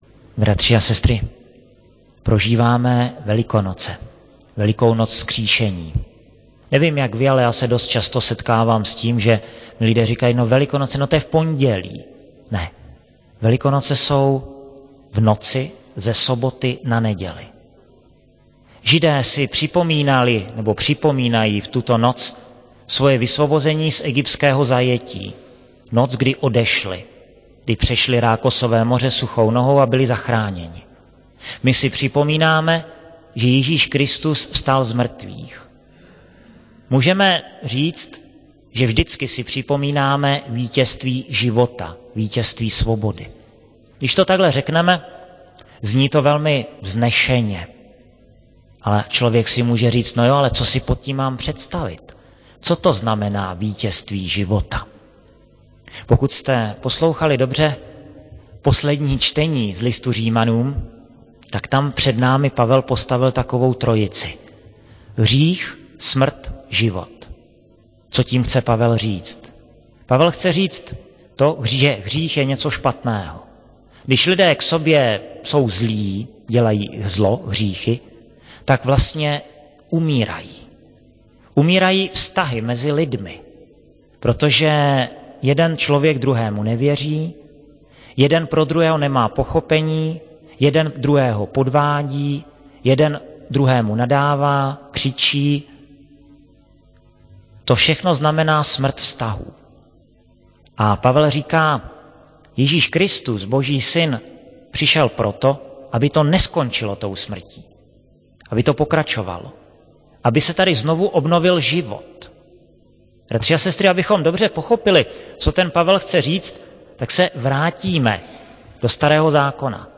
Rubrika Homilie
15.04.2006 - sobota , kostel sv. Jakuba ve Veverské Bítýšce
ZMRTVÝCHVSTÁNÍ PÁNĚ (Velikonoční vigilie)
[MP3, mono, 16 kHz, VBR 27 kb/s, 1.06 MB]